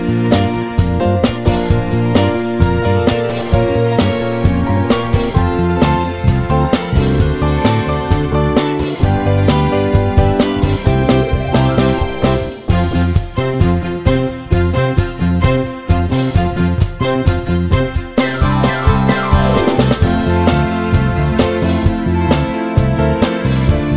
no Backing Vocals no guitars Disco 3:16 Buy £1.50